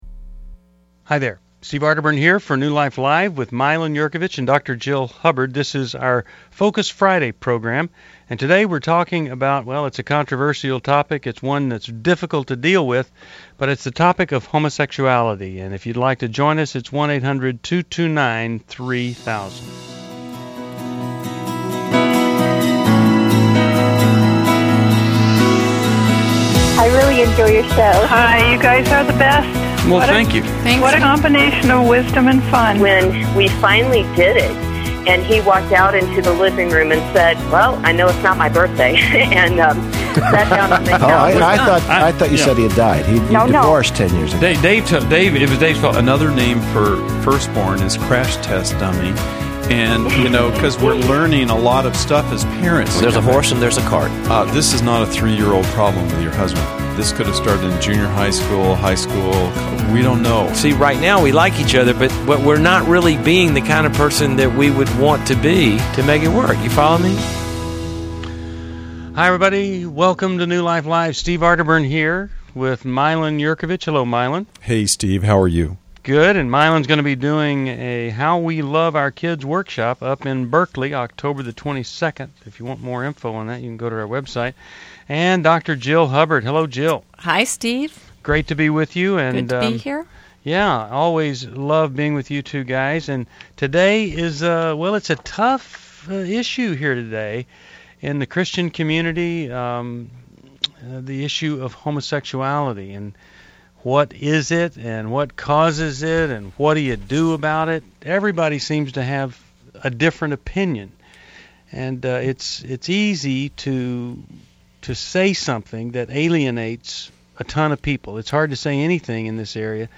Join the discussion on New Life Live: October 7, 2011, as hosts tackle questions on homosexuality, family dynamics, and finding support for LGBTQ+ loved ones.